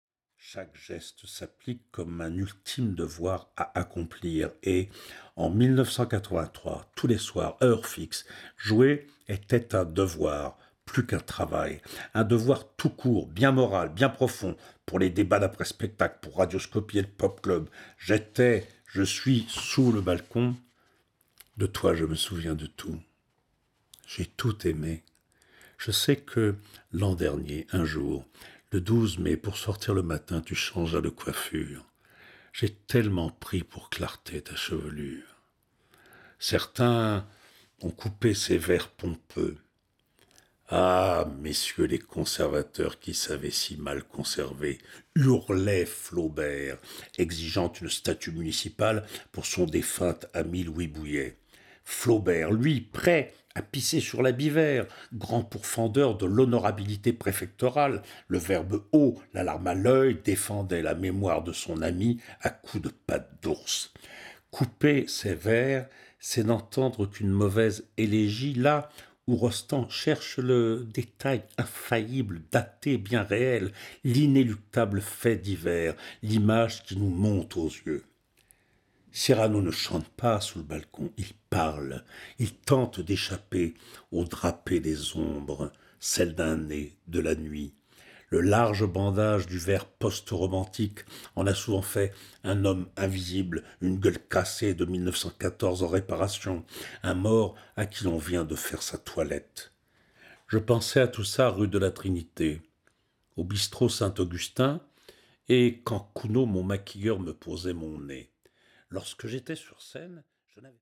Diffusion distribution ebook et livre audio - Catalogue livres numériques
Avec les siens, qui sont bien d’aujourd’hui » (Bernard Pivot - Le Journal du Dimanche – 26 novembre 2011) Au sommet de son art, Jacques Weber nous offre une lecture à la hauteur du grand comédien qu’il est, liant les rires les plus francs et généreux à l’émotion la plus dense… une grande confidence attendrie faite au public…